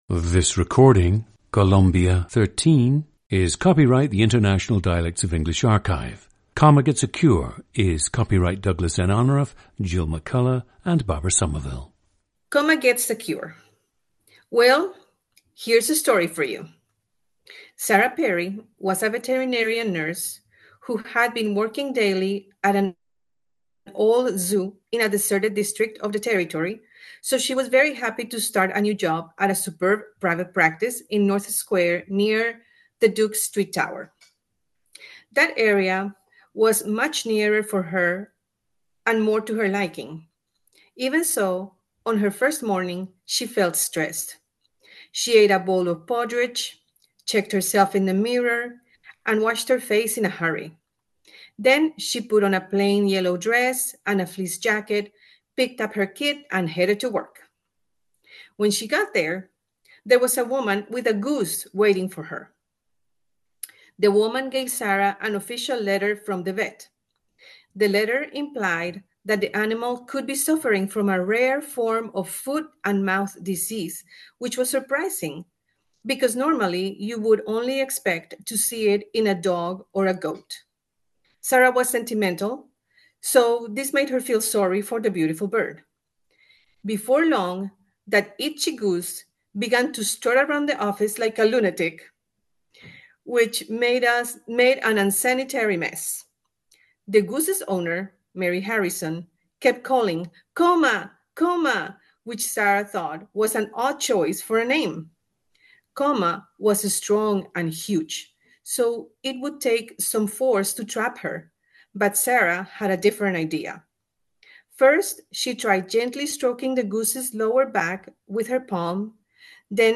GENDER: female
In this regard, she is a good example of a Colombian-American with a light accent.
She had an upper-middle-class upbringing in Colombia and is an experienced choral singer.
The subject actively worked on mastering a “neutral” English accent in order to interface better with patients who seemed to respond better to less heavily accented English.
The recordings average four minutes in length and feature both the reading of one of two standard passages, and some unscripted speech.